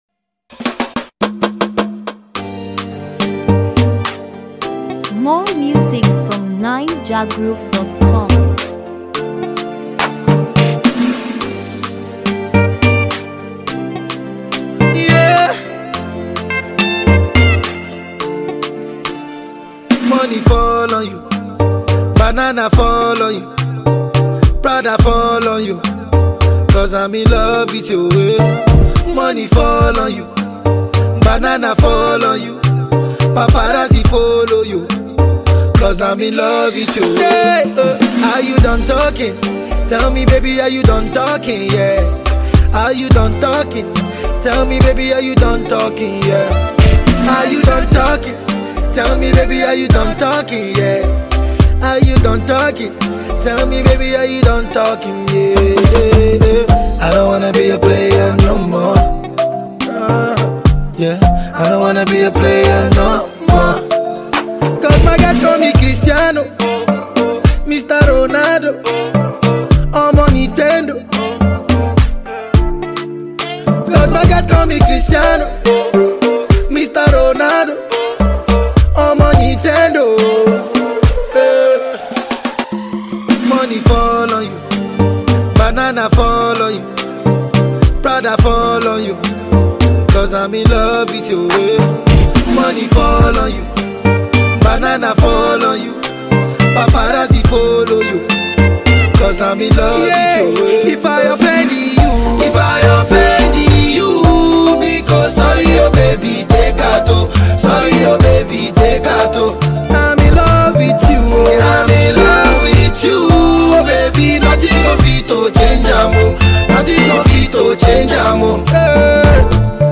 Naija-music